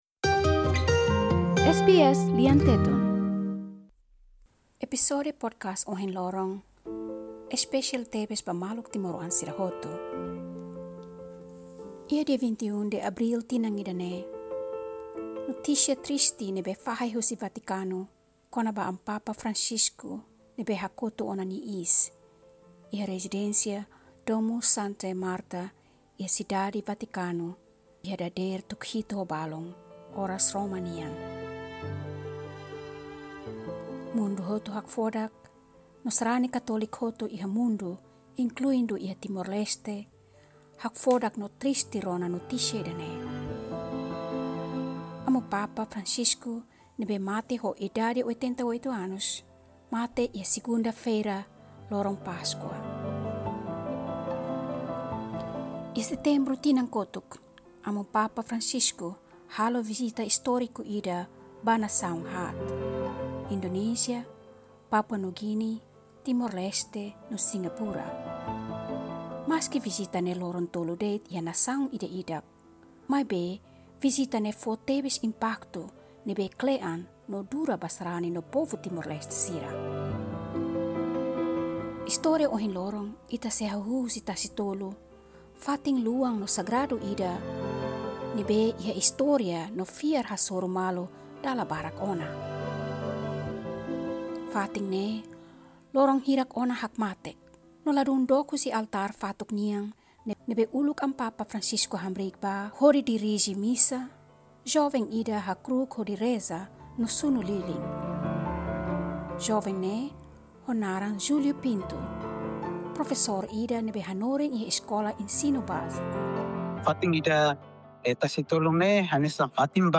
Iha epizódiu espesiál ida-ne’e, ita halo viajen filafali ba Amu Papa Francisco nia vizita istórika ba Timor-Leste iha fulan-Setembru tinan kotuk—momentu ida ne’ebé husik marka kle’an ba timor-oan sira. Liuhusi admiradór joven ida, sobrevivente kankru ida nian, akadémiku kulturál ida no Padre ida nia lian, ita hanoin hikas fali laos deit ema ne'ebé la'o hamutuk no hadomi povu, maibé mensajen ne'ebé nia husik hela.